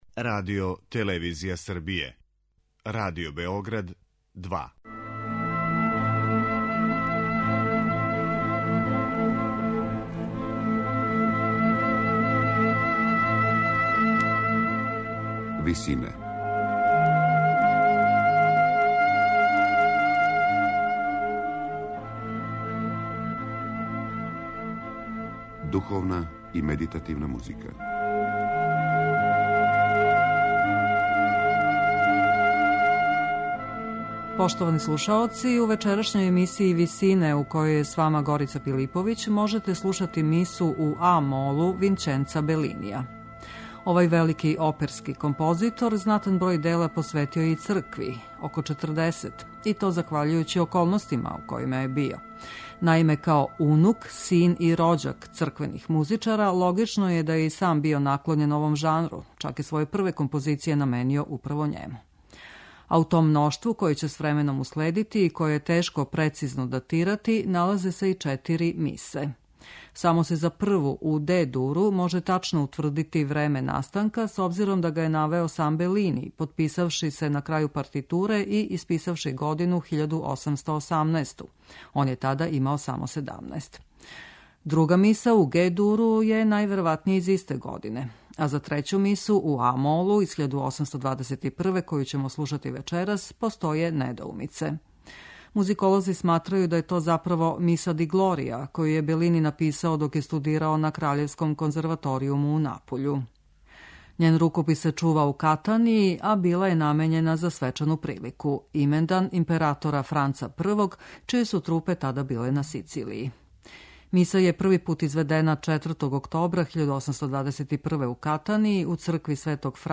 Друга миса, у Ге-дуру, највероватније је из исте године, а за трећу мису, у а-молу, из 1821, коју ћемо слушати вечерас, постоје недоумице.
у ВИСИНАМА представљамо медитативне и духовне композиције аутора свих конфесија и епоха.